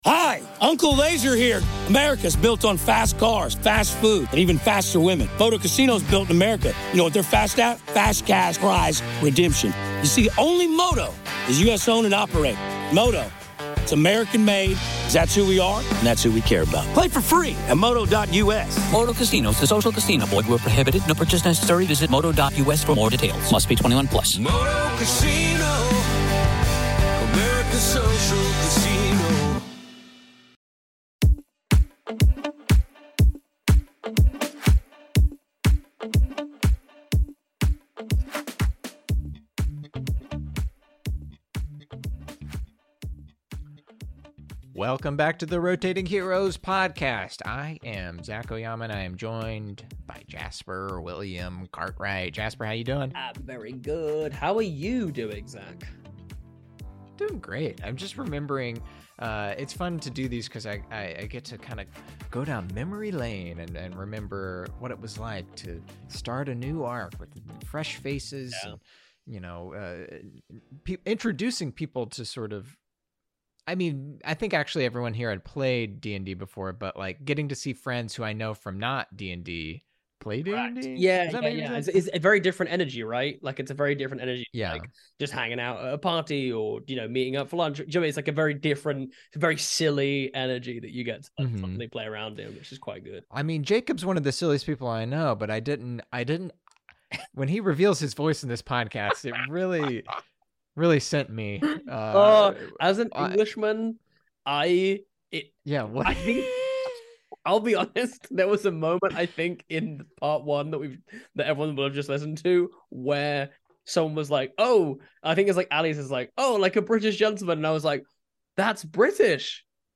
Zac Oyama was your Dungeon Master (Dimension 20, College Humour, Dropout, Adam Ruins Everything, Game Changer, and Um, Actually) Ally Beardsley plays Nancy-Rae Gan Mike Trapp was Trubine Spizzlezinc & Jacob Wysocki as Grib *Last Name Unrevealed*